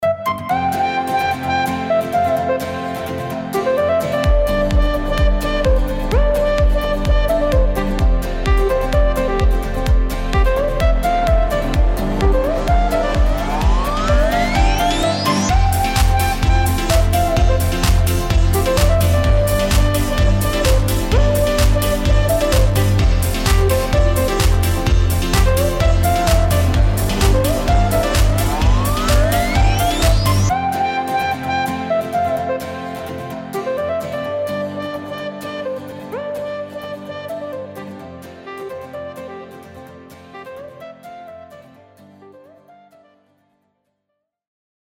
гитара
мелодичные
веселые
без слов
легкие
простые
Хорошая мелодия для будильника